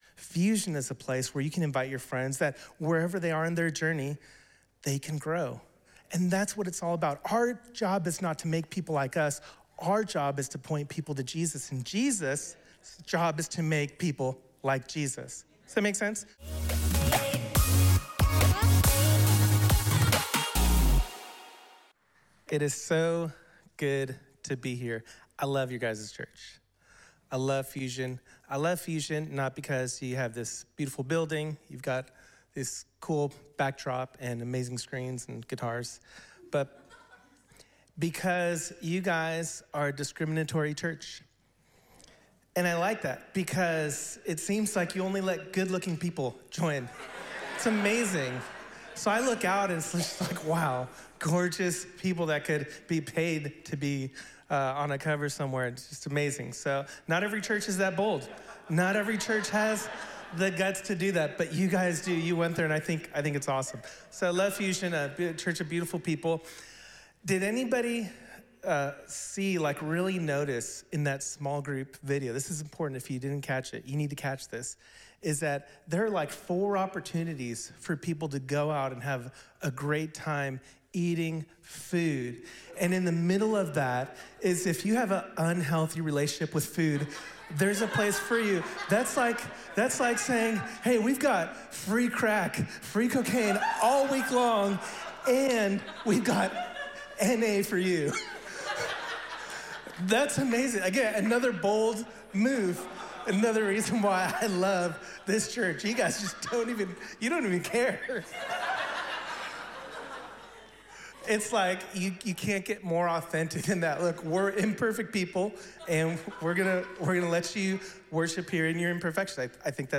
2025 The After Party Evangelism Sunday Morning This is part 22 of "The After Party